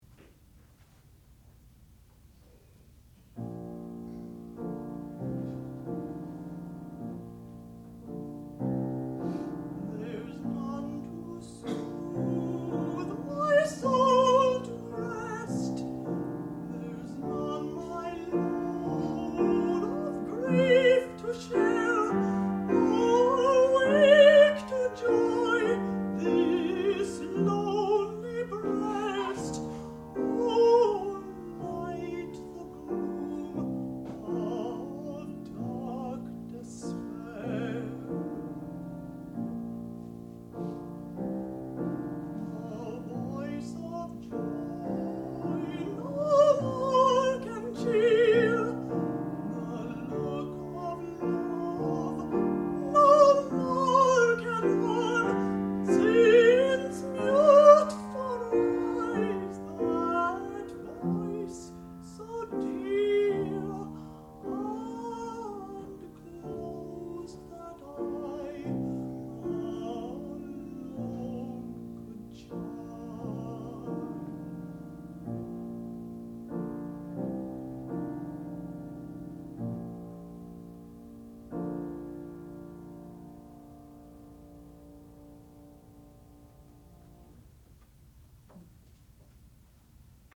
sound recording-musical
classical music
Master's Degree Recital
contralto